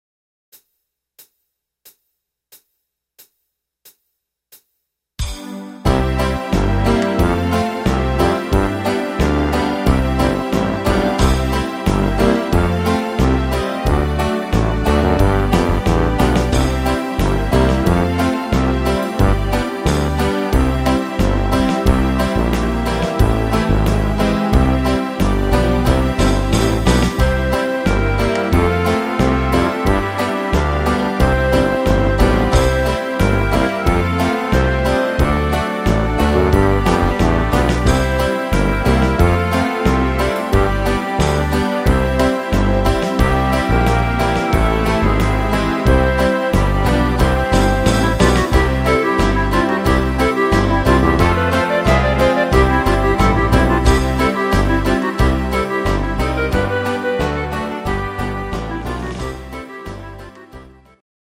instr. Piano